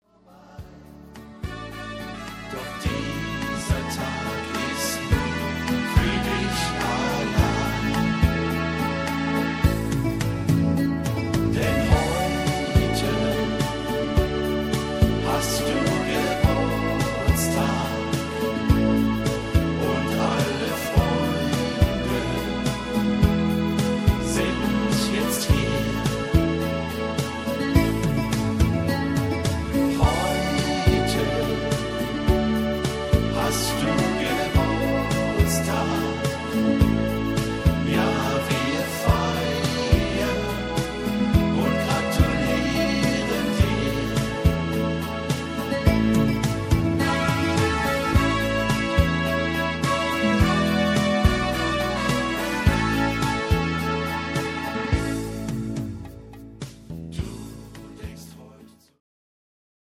Rhythmus  Beguine
Art  Deutsch, Volkstümlicher Schlager